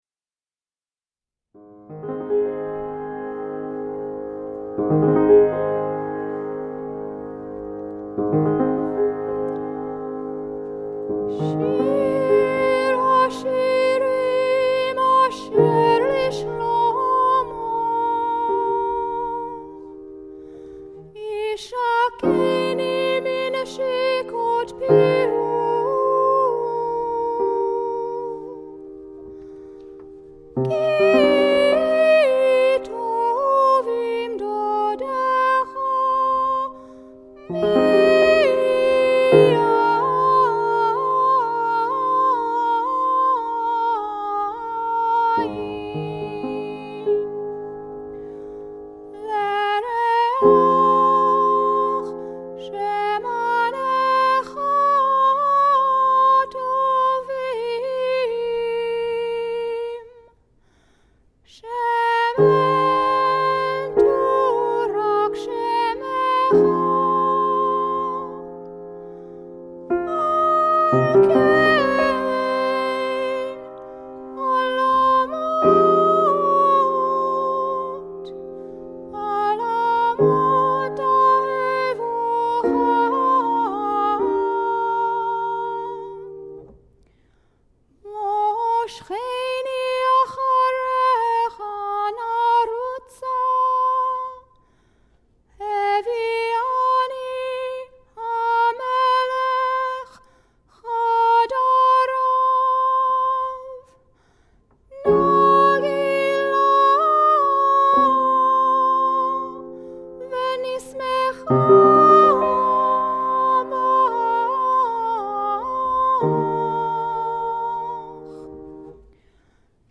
based on Georgian cantillation melodies
piano (recorded live